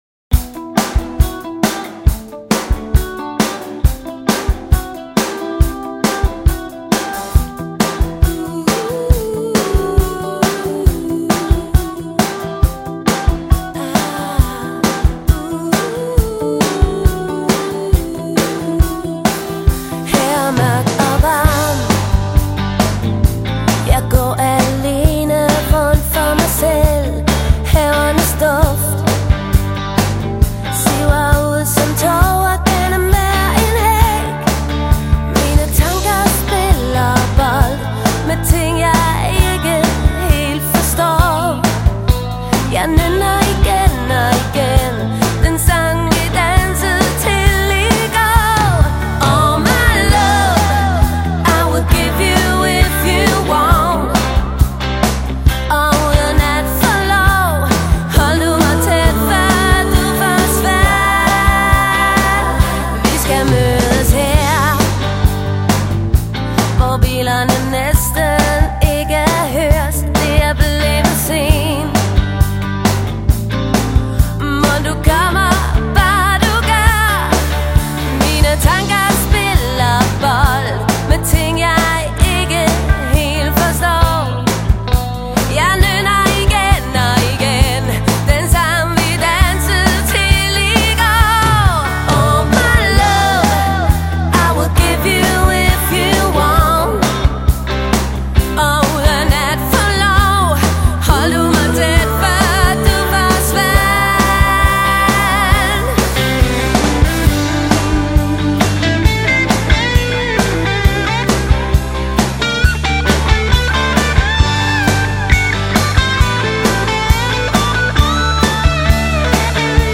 Det vil sige, bas, trommer, og nogle kor stemmer.